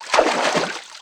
STEPS Water, Walk 10.wav